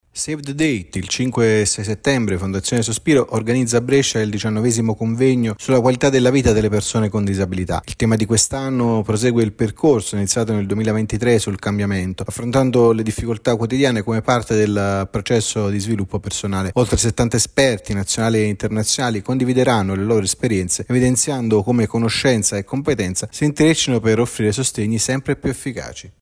In Abruzzo il camp inclusivo che vede protagonisti giovani con disabilità. Il servizio